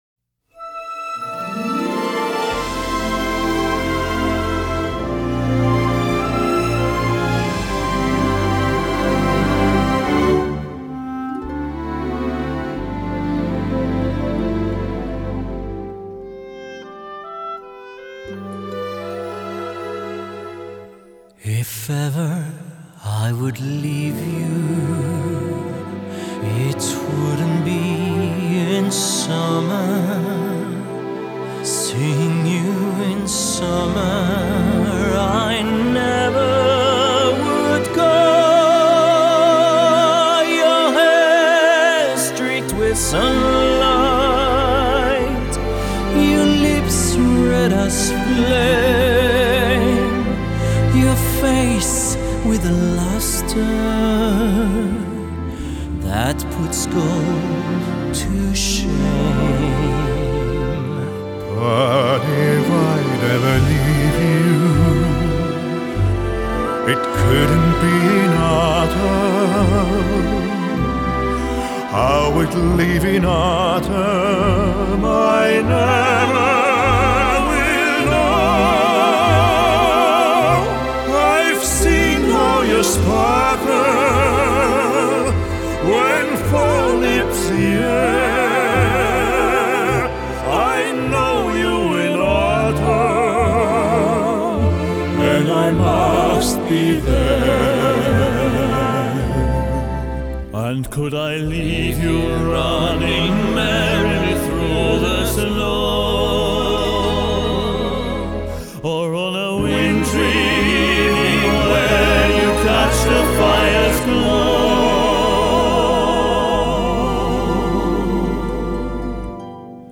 Genre: Pop, Crossover, Musical